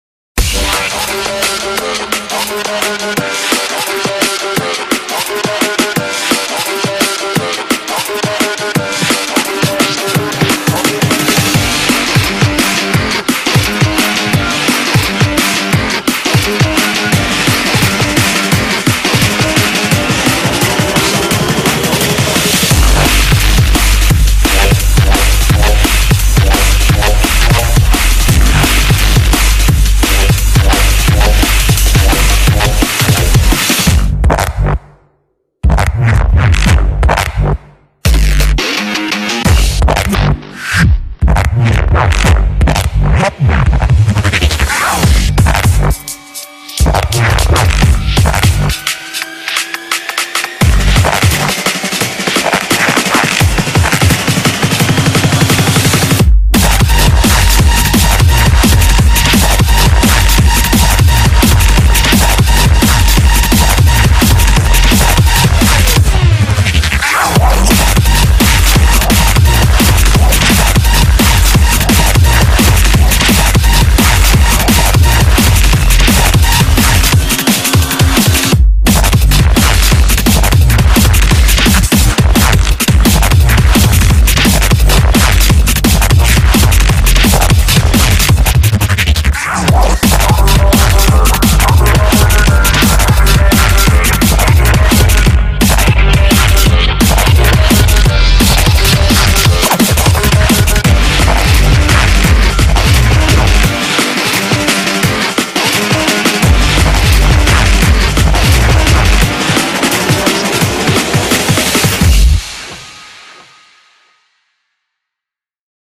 BPM86-172
Audio QualityMusic Cut